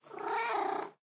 purreow2.ogg